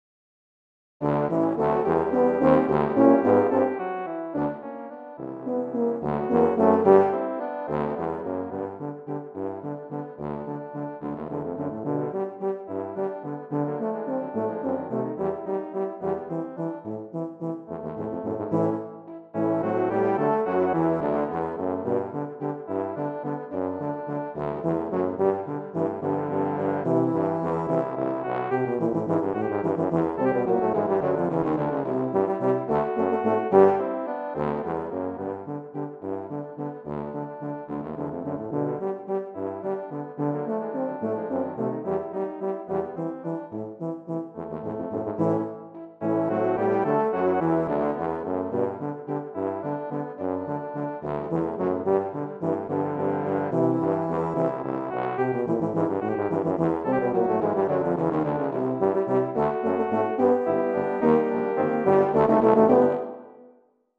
Voicing: Tuba Ensemble